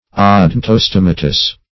Search Result for " odontostomatous" : The Collaborative International Dictionary of English v.0.48: Odontostomatous \O*don"to*stom"a*tous\, a. [Odonto- + Gr.
odontostomatous.mp3